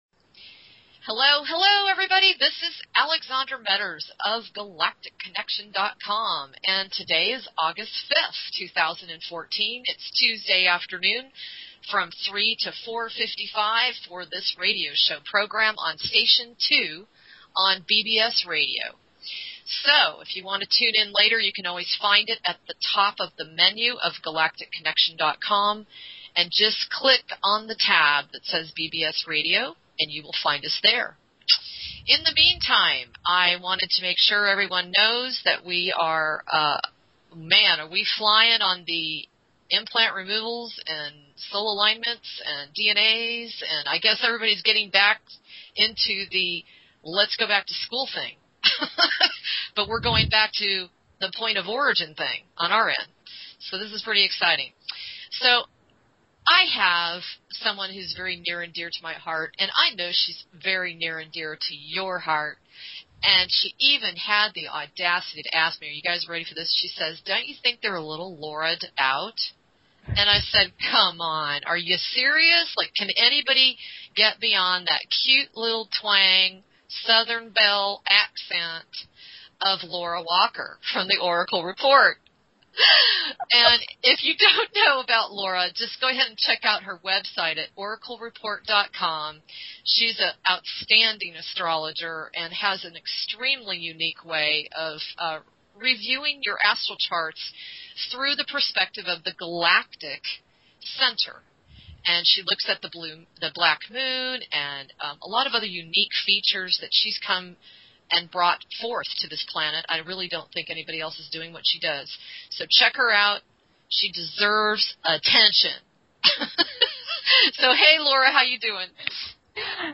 Headlined Show, Galactic Connection August 5, 2014